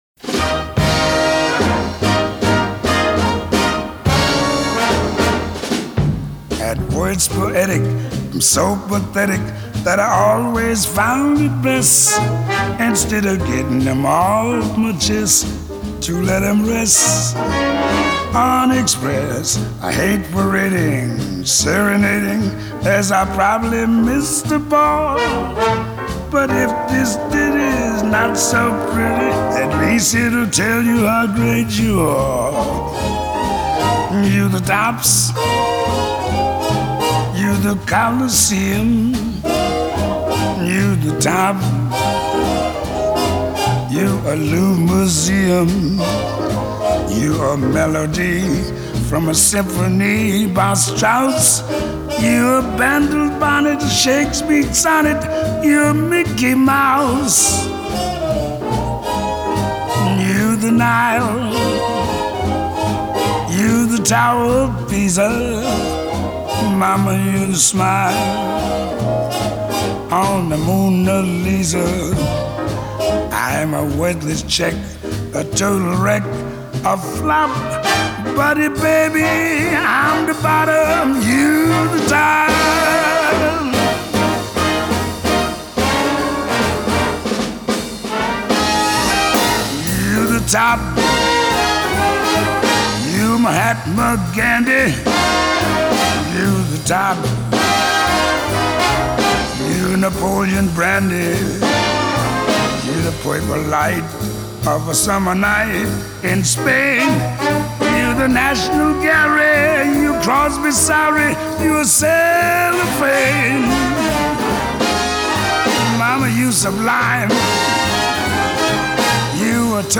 1934   Genre: Musical    Artist